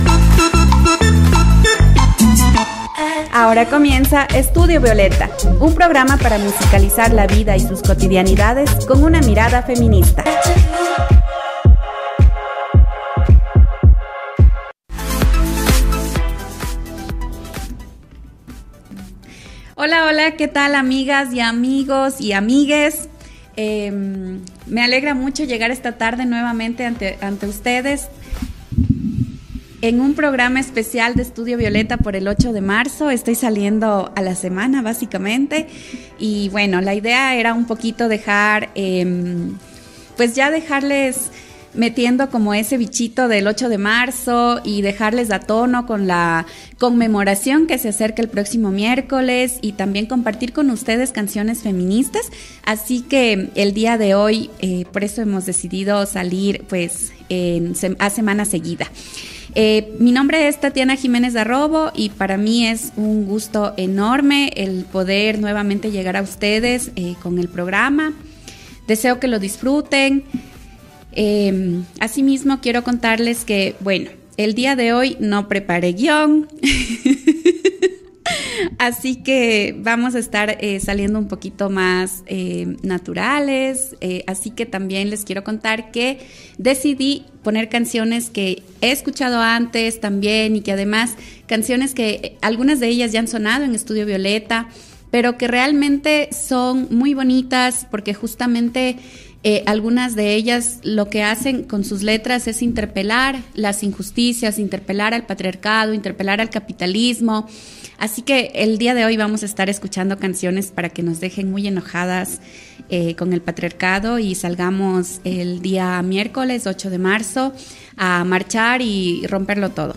En la tercera edición de esta 2da temporada de Studio Violeta y como antesala a la conmemoración del Día Internacional de la Mujer, dialogamos y escuchamos canciones que visibilizan las causas feministas.